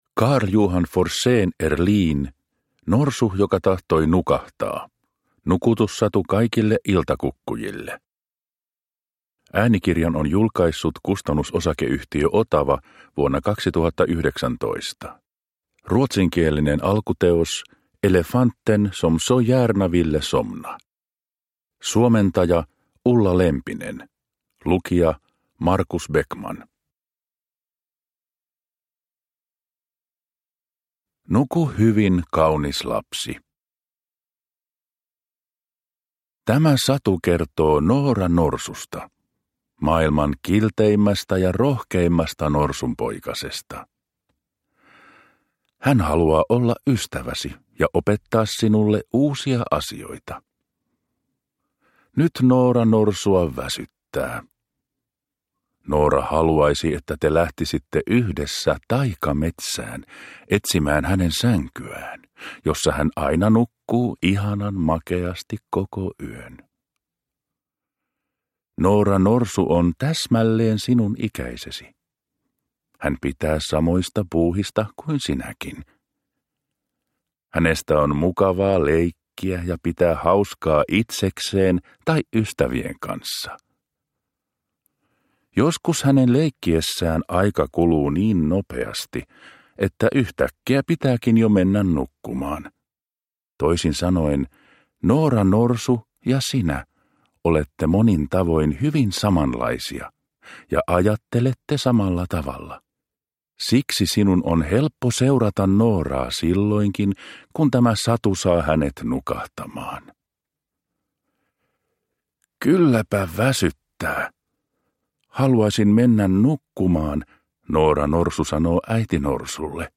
Norsu joka tahtoi nukahtaa – Ljudbok – Laddas ner